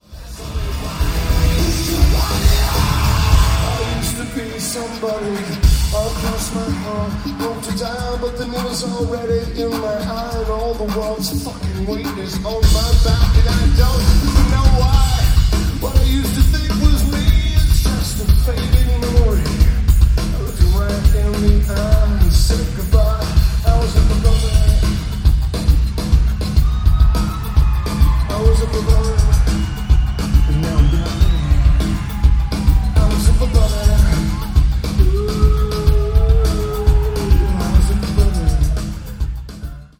Lakewood Amphitheatre
Lineage: Audio - AUD (DPA 4060 (HEB) + BB + Edirol R09)